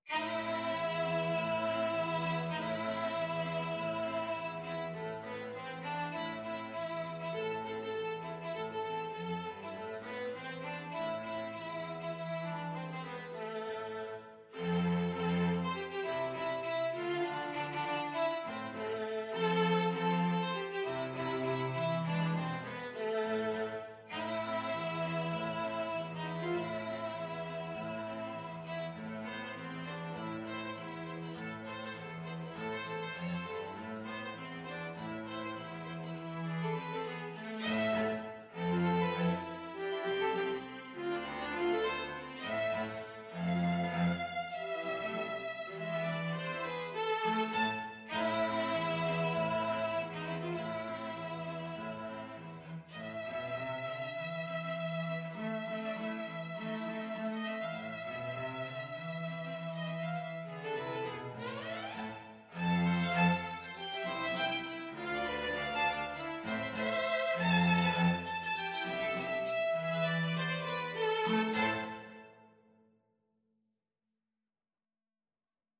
String trio version
ViolinViolaCello
Firmly, with a heart of oak! Swung = c.100
2/2 (View more 2/2 Music)